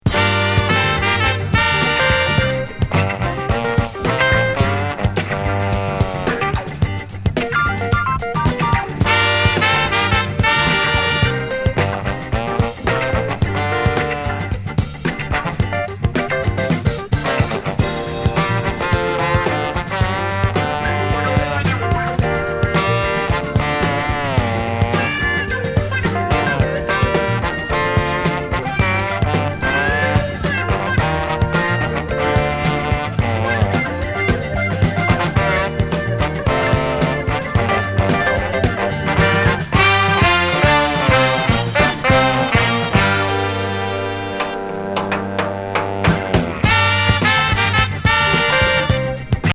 謎の覆面グループによる国内企画ポップス・カヴァー集!レア・